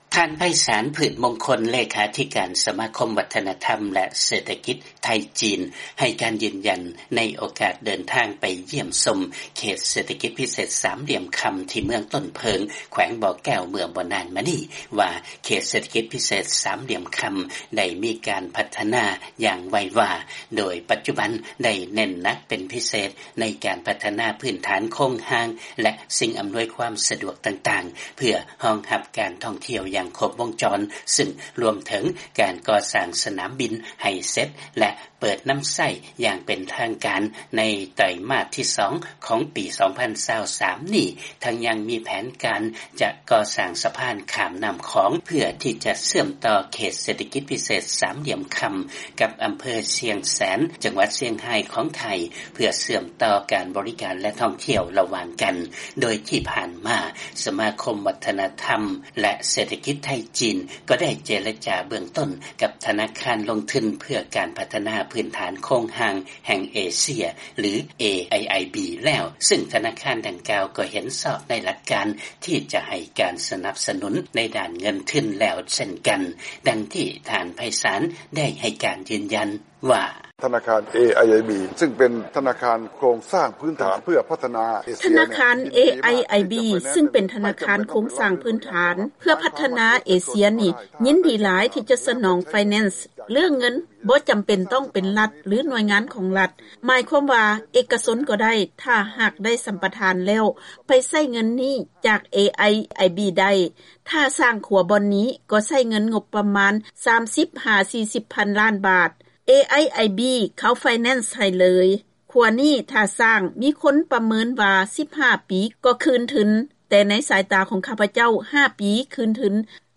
ມີລາຍງານເລື້ອງນີ້ມາສະເໜີທ່ານຈາກບາງກອກ.